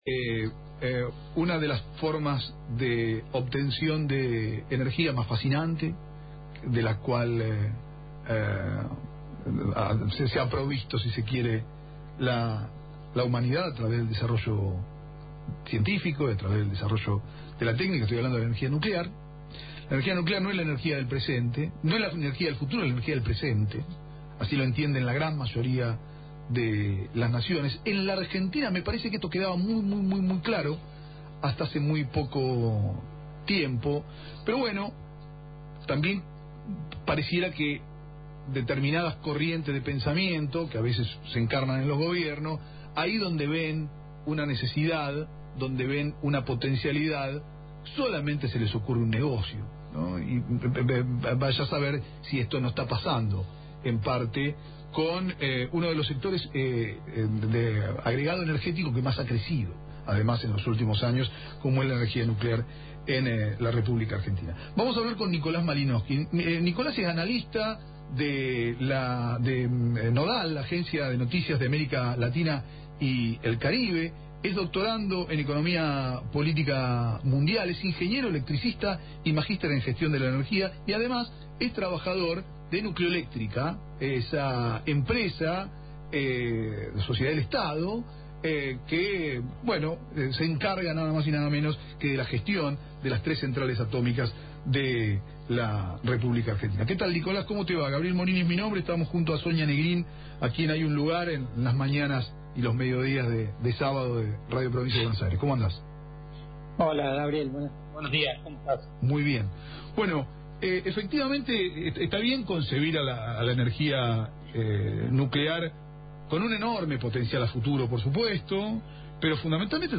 ingeniero Electricista y Magíster en Gestión de la Energía conversó con Es Un Montón por Radio Provincia